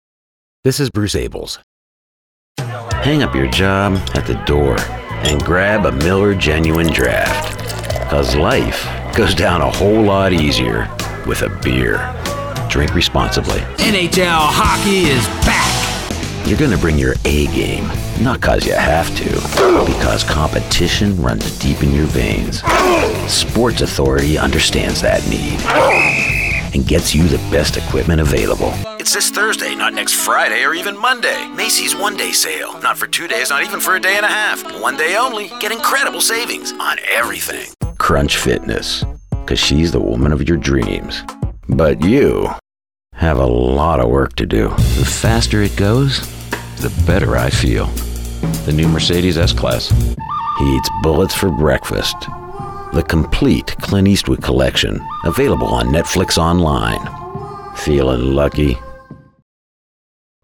A versatile VO pro with a deep, warm voice and distinctive overtones.
englisch (us)
Sprechprobe: Werbung (Muttersprache):
My voice is like smooth gravel; deep, textured, and unforgettable.
I work from a broadcast-quality home studio equipped with Source-Connect.